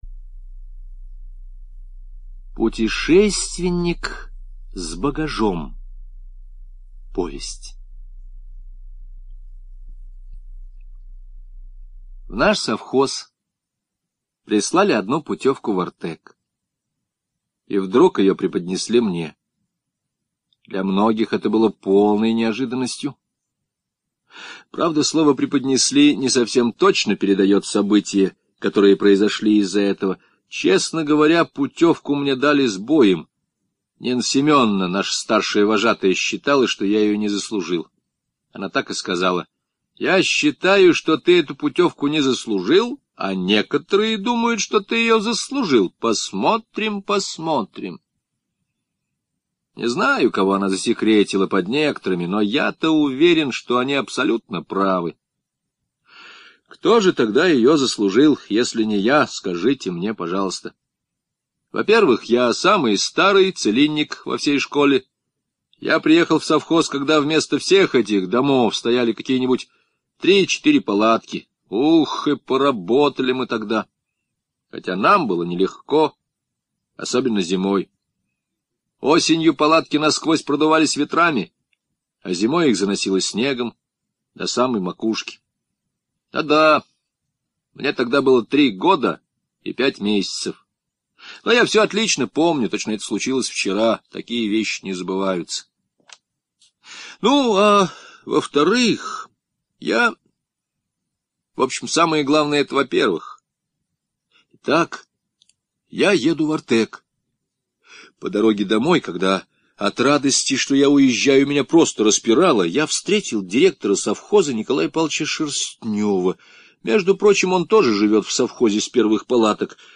Путешественник с багажом - аудио повесть Железникова - слушать онлайн